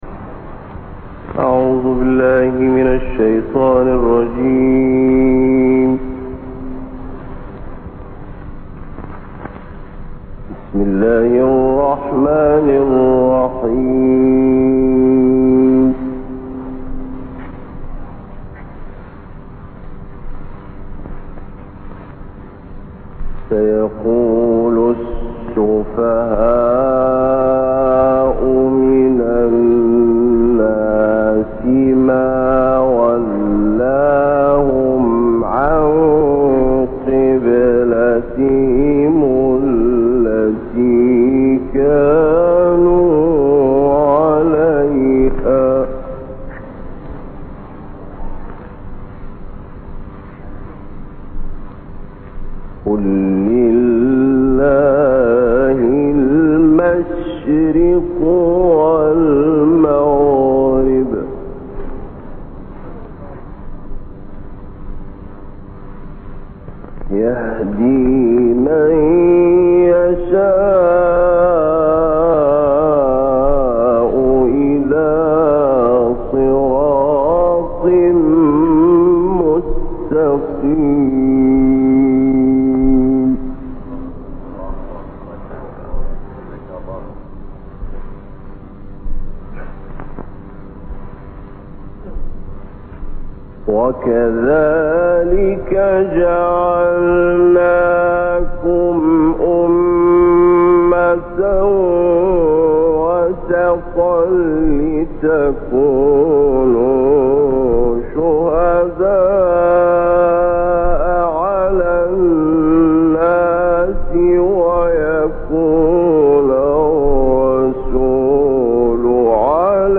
تلاوتی ماندگار از شیخ محمد اللیثی در مسجد حضرت زینب (س) قاهره
قاری مصری